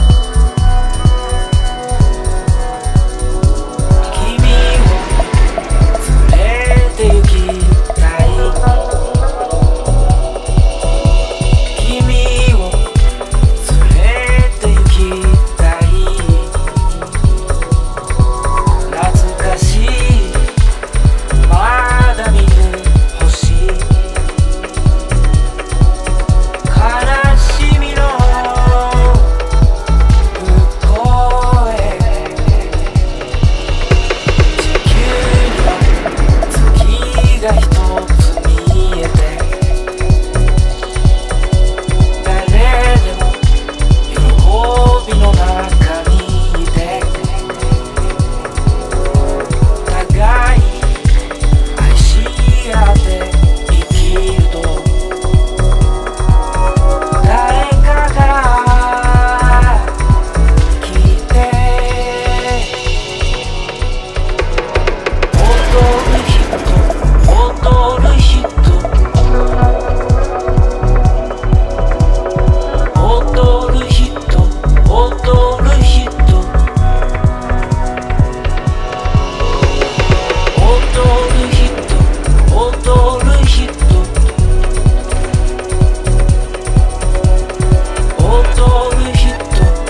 WORLD / OTHER / AFRO BEAT
両面とも呪術的な妖しいさと牧歌的な魅力が混じり合い、ハウス/ワールド系DJをまとめて虜にします！